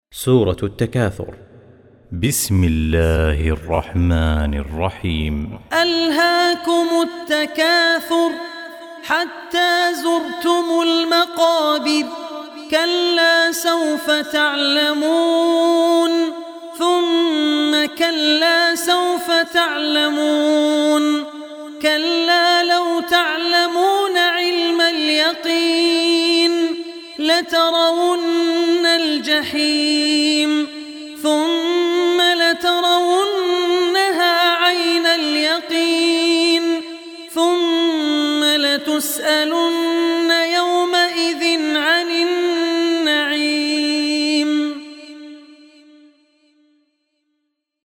Surah Takasur Recitation by Abdur Rehman Al Ossi